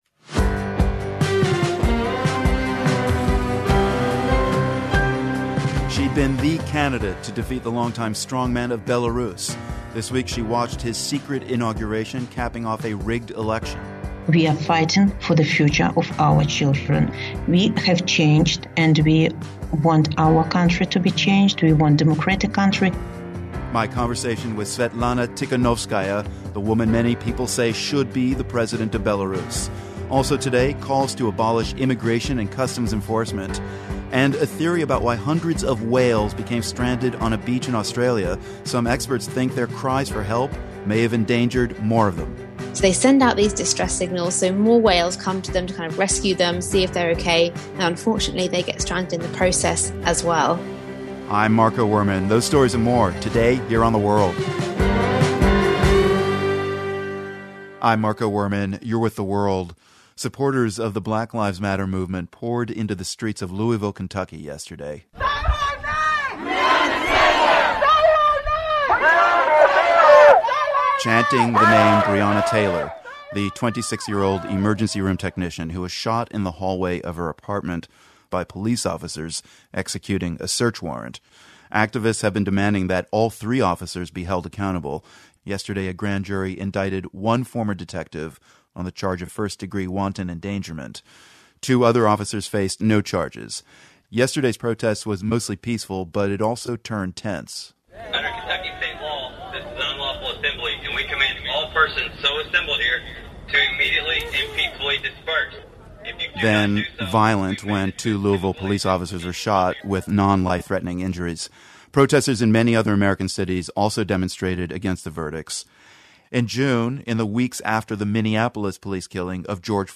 Thursday's announcement that the police officers involved in the March killing of Breonna Taylor would not face homicide charges is yet another low for activists and supporters of the Black Lives Matter movement around the world. And, protesters in Belarus have been in the streets since early August calling for the resignation of President Alexander Lukashenko. The World's host Marco Werman speaks with Belarusian opposition leader Svetlana Tikhanovskaya.